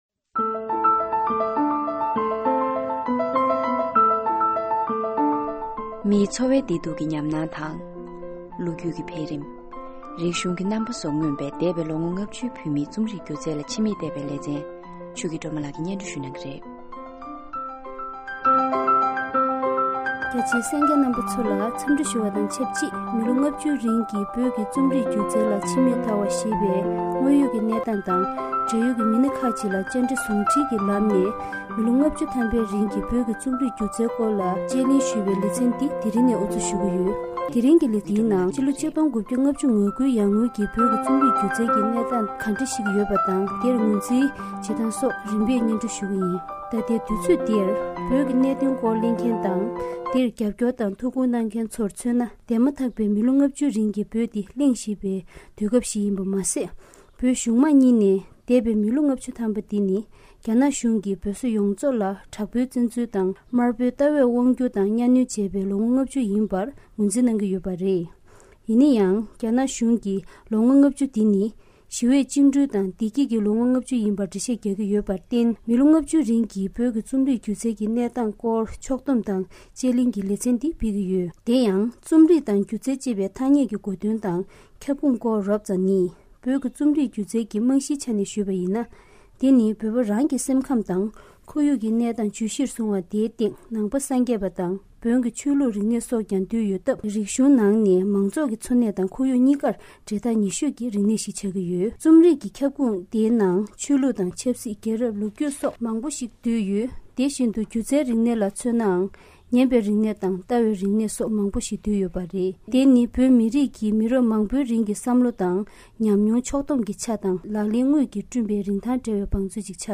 བོད་ཀྱི་རྩོམ་རིག་སྒྱུ་རྩལ་སྐོར་དཔྱད་གླེང་རླུང་འཕྲིན་སྙན་སྒྲོན་ལེ་ཚན།
༼རྩོམ་ཡིག་འདི་ནི། ཨ་རིའི་རླུང་འཕྲིན་ཁང་གི་བོད་སྐད་ཚན་པས་བོད་ཀྱི་རྩོམ་རིག་སྐོར་སྙན་སྒྲོན་གྱི་གོ་སྐབས་གནང་བའི་སྐོར་དེ་ཡིན། འདིར་སྙན་སྒྲོན་ཡི་གེའི་མ་ཕྱི་དང༌། སྙན་སྒྲོན་དངོས་གཞི་རྒྱང་བསྲིང་གི་སྒྲ་ཡང་ལྷན་དུ་བཀོད་ཡོད།༽